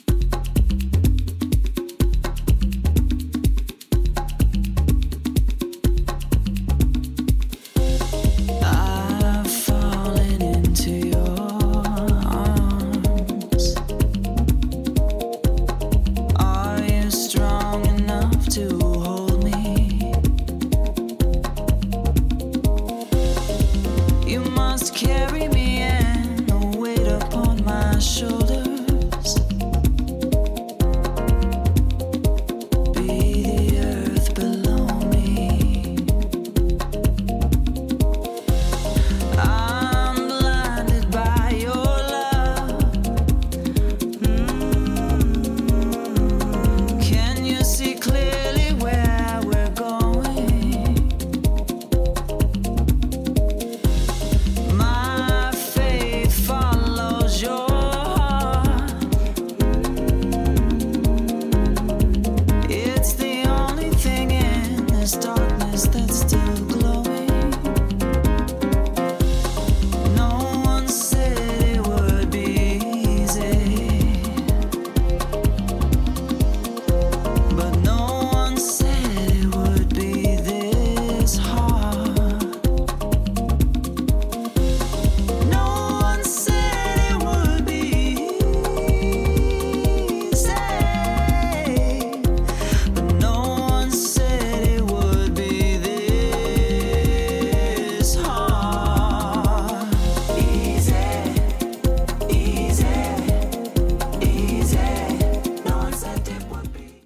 melodic Rhodes production
silky vocals with MAW style rhythms